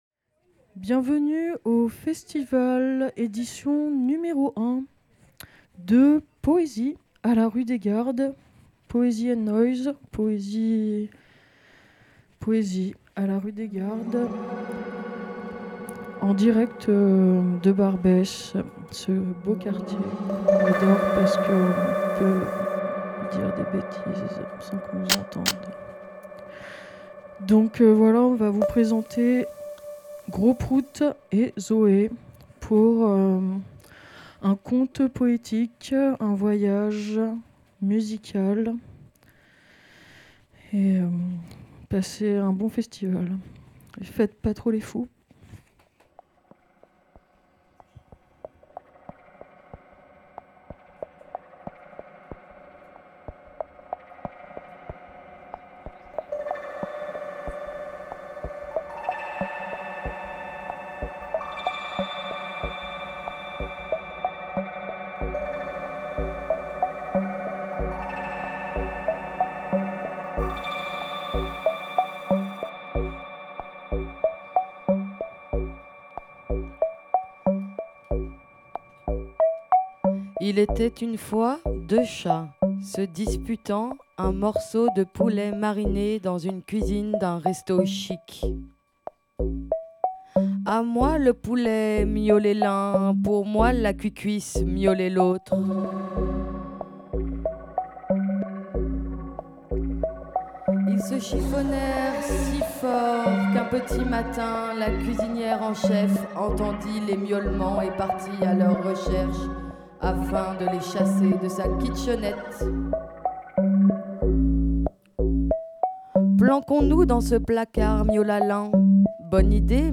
une comptine improvisée
à l'occasion du week end de poesie numero uno